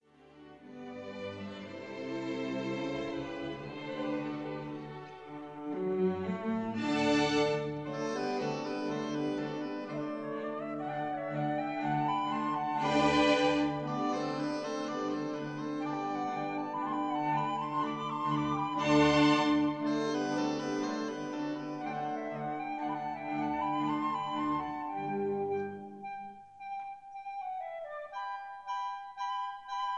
Concertante: Andante grazioso in G major
Rondeau: Allegro ma non troppo in G major